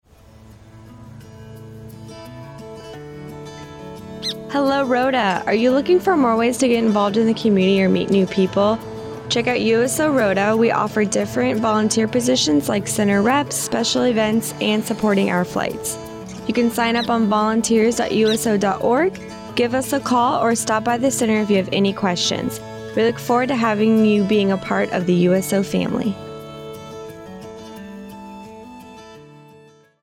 AFN Radio Spot, Volunteering with the USO